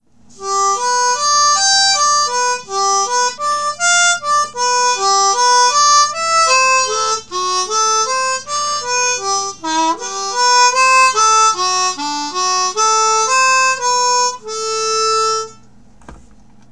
We’re using a G major diatonic and playing in 1st position.